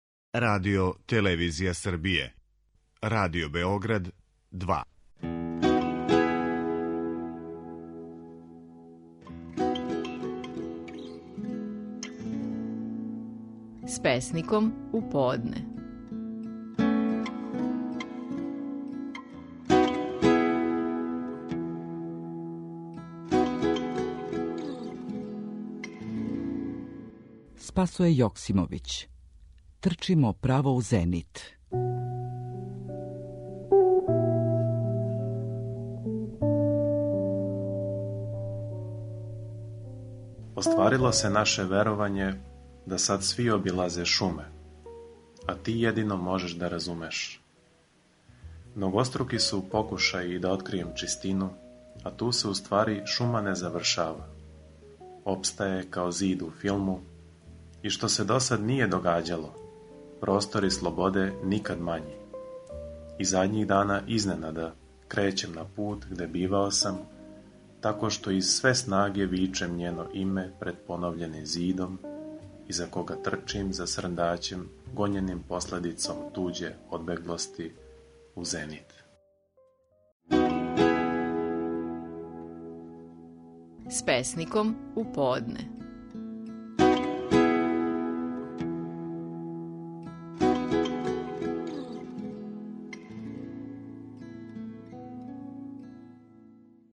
Стихови наших најпознатијих песника, у интерпретацији аутора.
Спасоје Јоксимовић говори своју песму „Трчимо право у зенит".